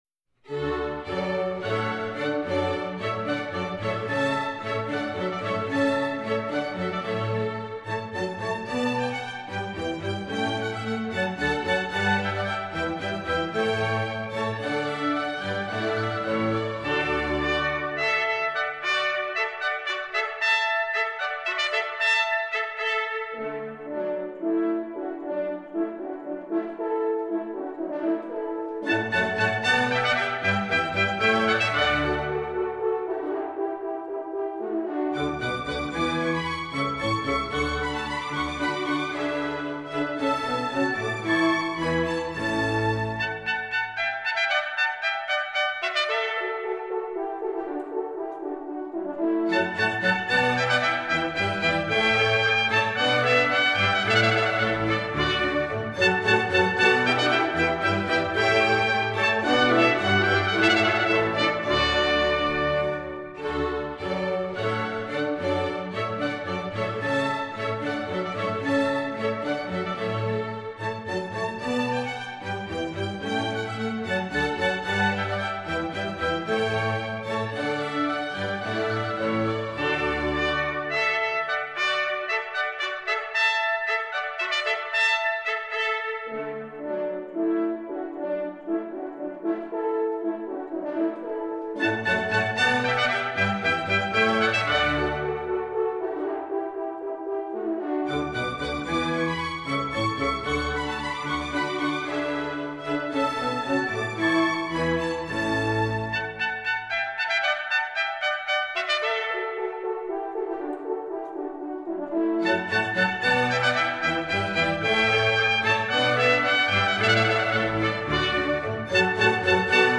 First, it is in a traditional A-B-A sonata form.
This “B” section is completely different from the familiar Sailor’s Hornpipe theme.